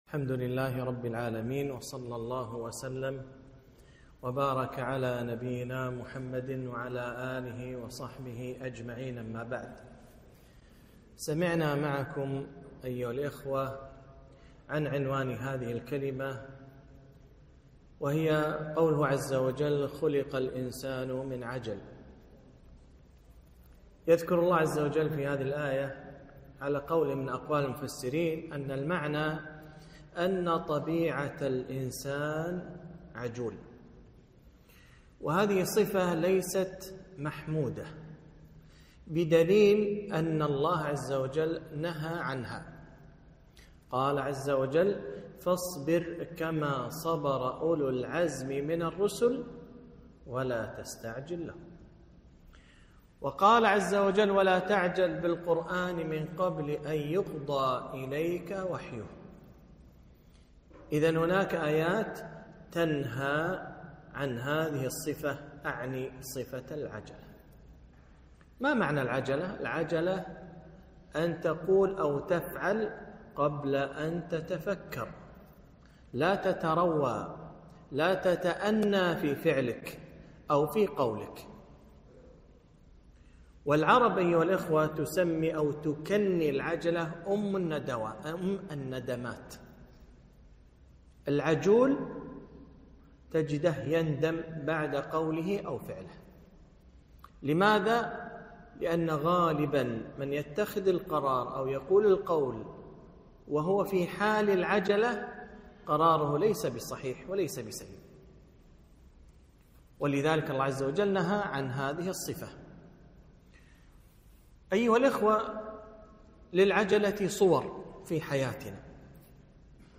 محاضرة - قوله تعالى: ( خُلق الإنسانُ من عجل )